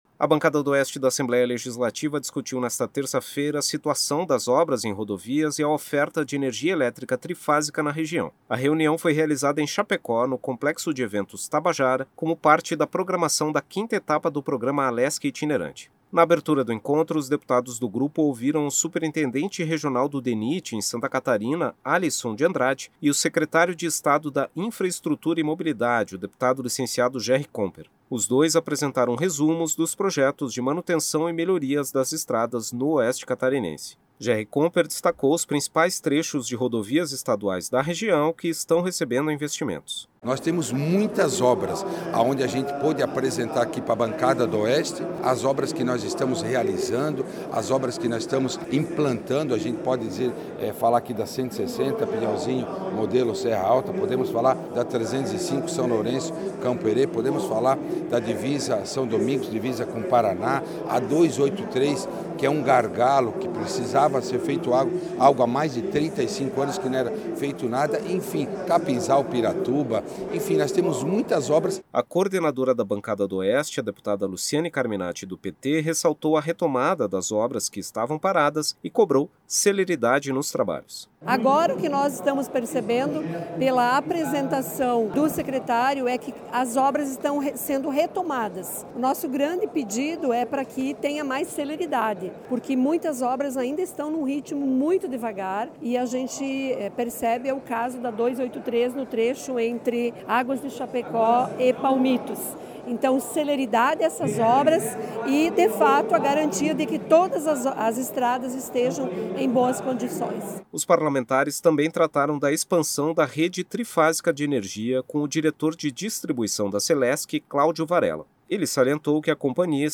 A Bancada do Oeste da Assembleia Legislativa discutiu nesta terça-feira (26) a situação das obras em rodovias e da oferta de energia elétrica trifásica na região. A reunião foi realizada em Chapecó, no Complexo de Eventos Tabajara, como parte da programação da quinta etapa do programa Alesc Itinerante.
Entrevistas com:
- deputada Luciane Carminatti (PT), coordenadora da Bancada do Oeste do Assembleia Legislativa;
- deputado licenciado Jerry Comper, secretário de Estado da Infraestrutura e Mobilidade;